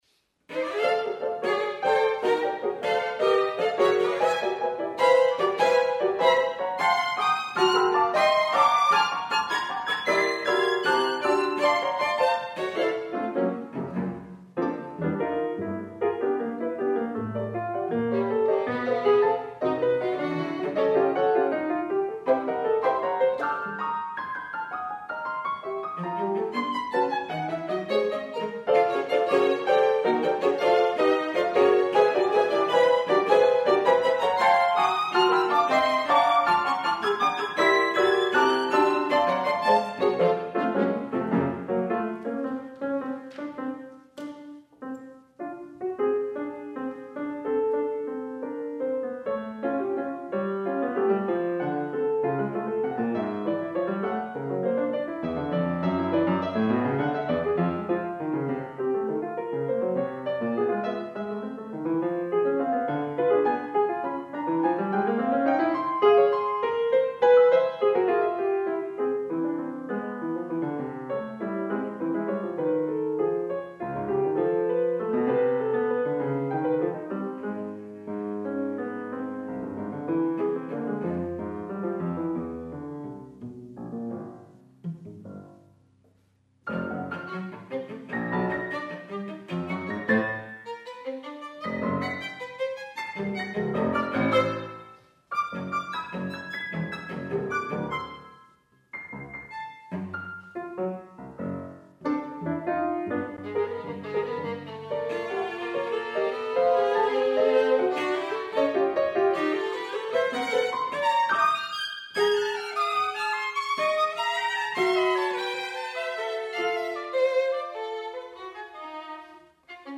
Scherzo
piano
violin
cello